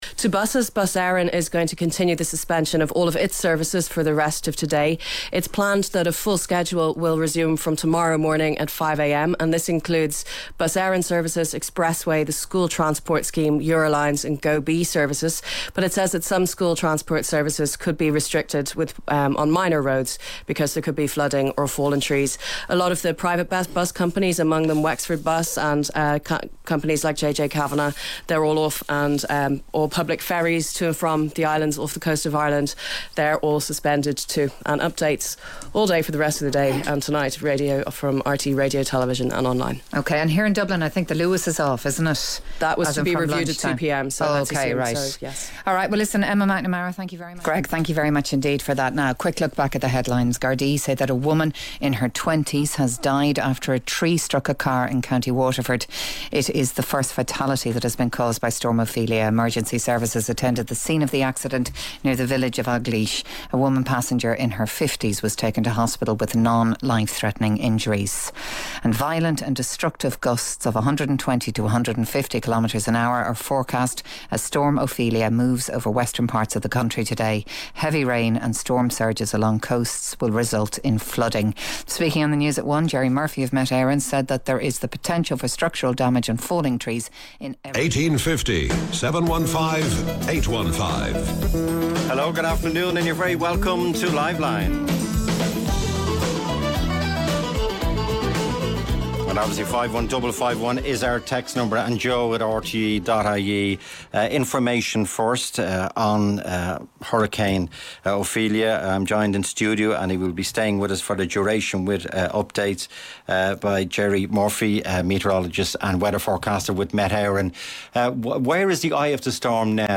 As Ophelia arrived across Ireland on Oct 16th 2017, hear how the likes of stations such as RTE, Newstalk, Today FM, Midlands 103, Spin, 98 FM, WLR FM coped.